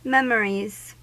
Ääntäminen
Ääntäminen US Haettu sana löytyi näillä lähdekielillä: englanti Käännöksiä ei löytynyt valitulle kohdekielelle. Memories on sanan memory monikko.